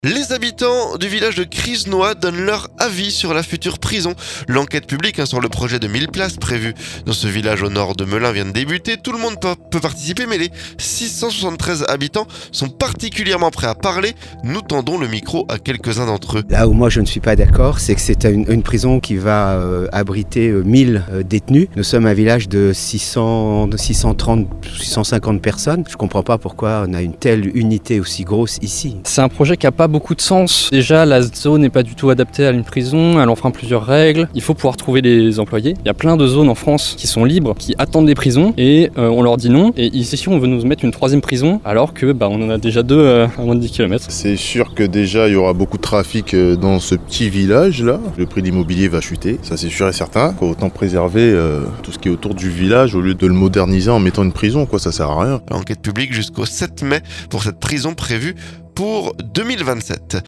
Les habitants de Crisenoy donnent leur avis sur la future prison. L'enquête publique sur le projet de 1.000 places prévu dans ce village au nord Melun vient de débuter.
Nous tendons le micro à quelques-uns d'entre eux.